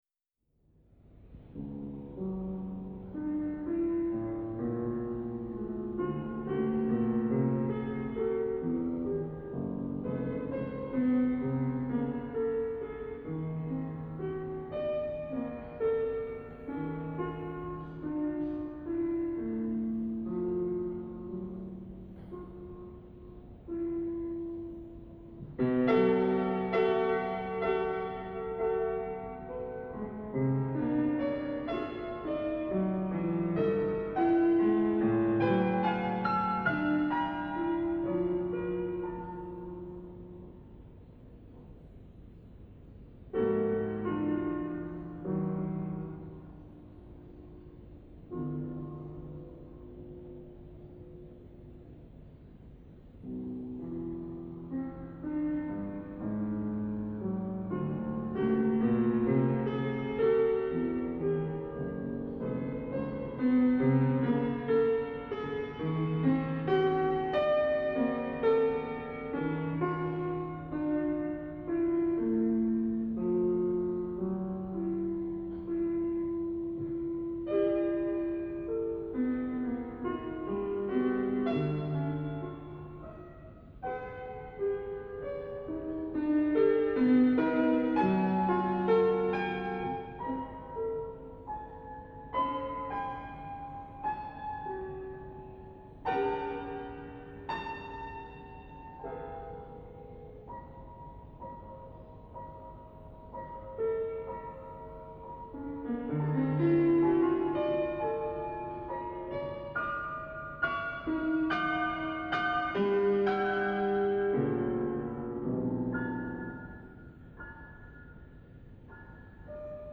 2. Satz (Andante) meiner 3. Klaviersonate von 1989.
Among others I composed at that time a solo sonata for violin, a piano sonata with three movements and a cyle of eight pieces "Recitationi per una voce" for soprano solo.
At this opportunity I performed the second movement (Andante) from my third piano sonata.